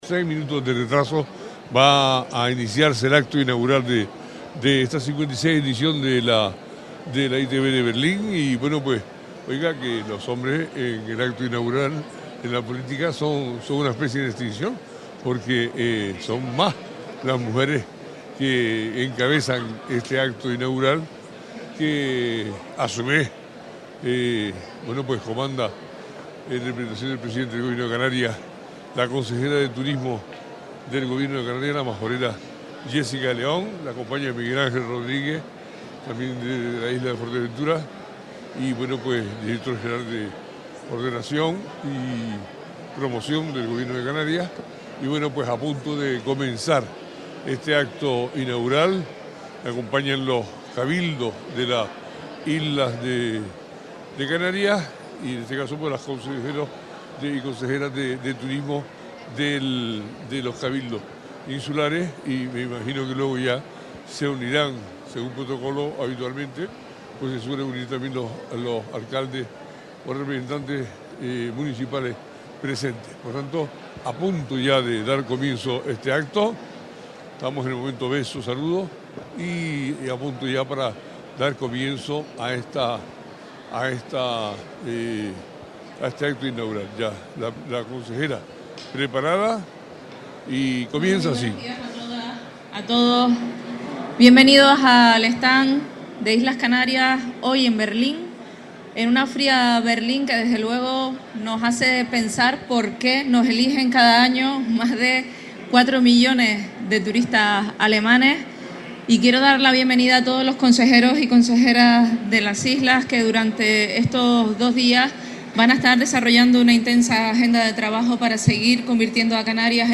Acto inaugural ITB Berlín 2025
Asistimos al acto inaugural de Canarias en la Feria Internacional ITB de Berlín 2025, una de las más importantes del turismo a nivel mundial. La encargada de dar inicio a la actividad del archipiélago en esta cita ha sido consejera de Turismo del Gobierno de Canarias, Jessica de León.